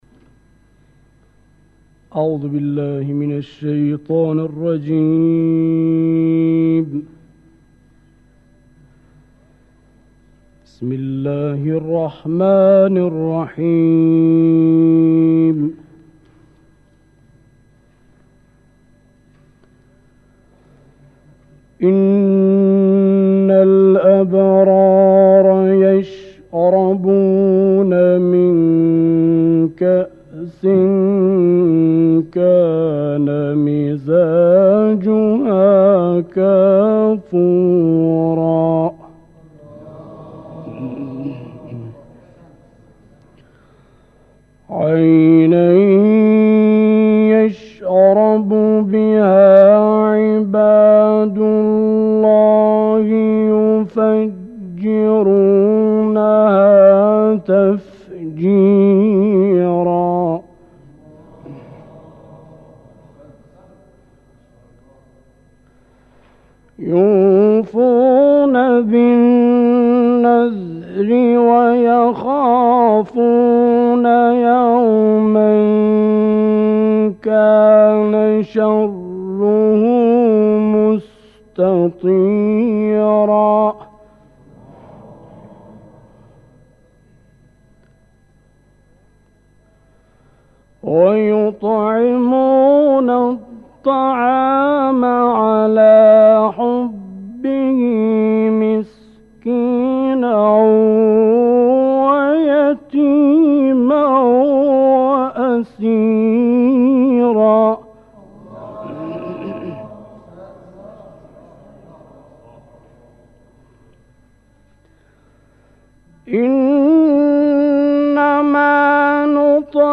این تلاوت کوتاه در دهه 60 اجرا شده است.